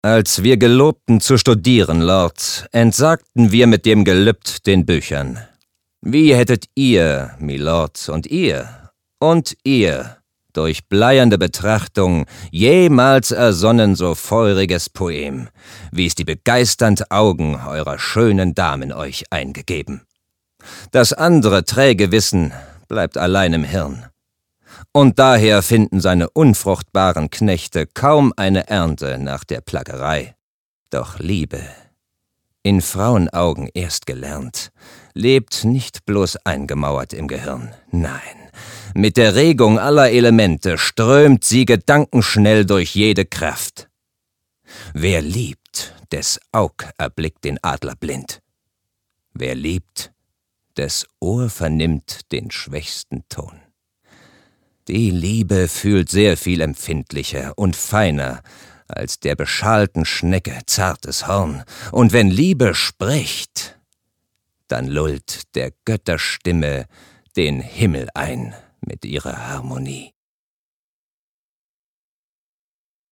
plakativ
Mittel plus (35-65)
Lip-Sync (Synchron)